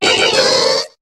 Cri de Lumivole dans Pokémon HOME.